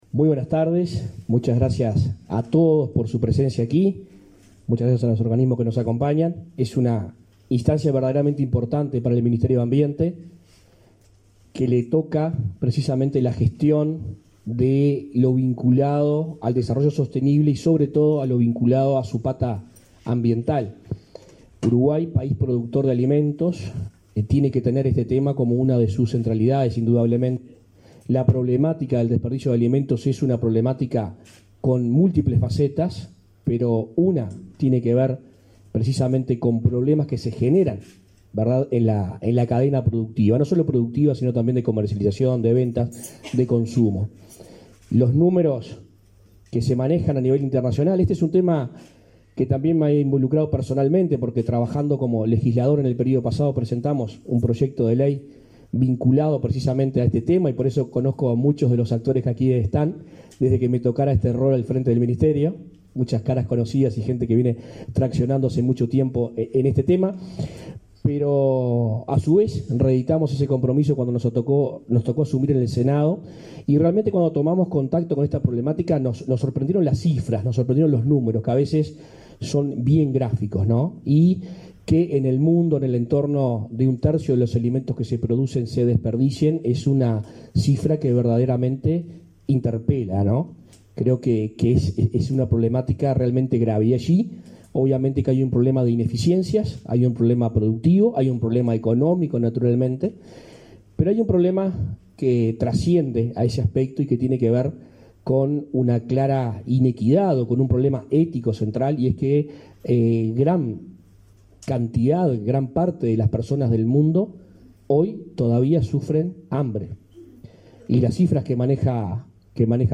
Conferencia por presentación del Comité Nacional para la Prevención y Reducción de Pérdidas y Desperdicios de Alimentos en Uruguay
conferencia.mp3